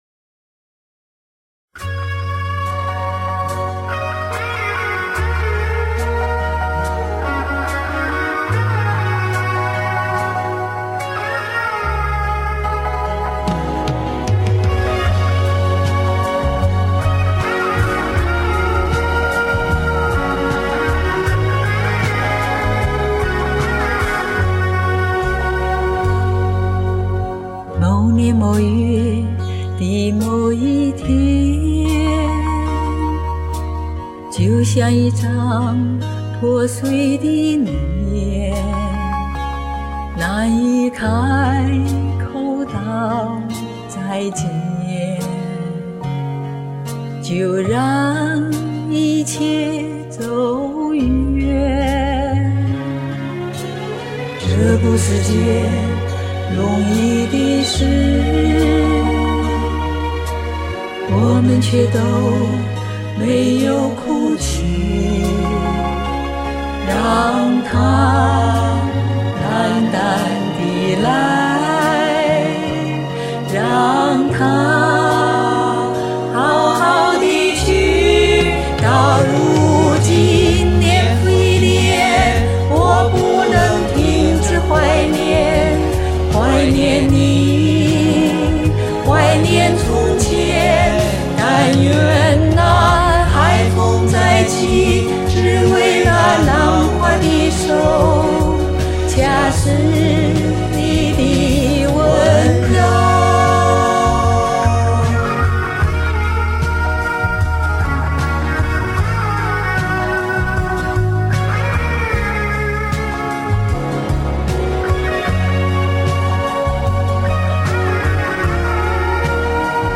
第二段开始前好像有点杂音没去掉
你的和声好像和伴奏混在一起，有点靠后，听不明显，伴奏还是不太好。
老歌老人老情感。。。和声很好听
温柔的歌声，感人的故事！